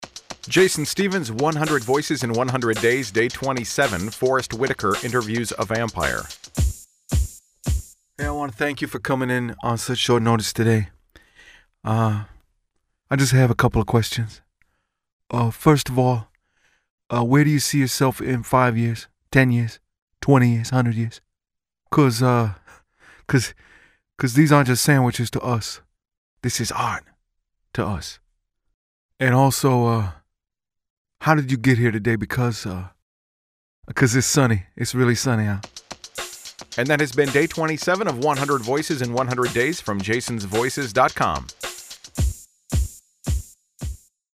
First, my Forest Whitaker impression.
Tags: celebrity voices, Forest Whitaker impersonation